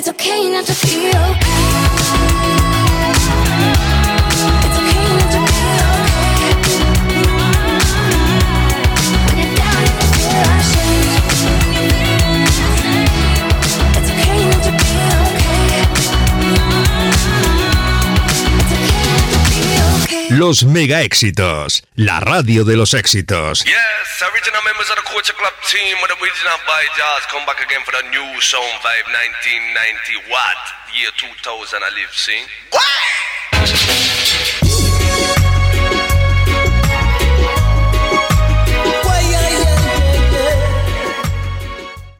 Temes musicals i eslògan.
Música, identificació de la ràdio i tema musical.
Musical